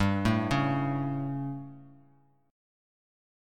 Gsus2b5 chord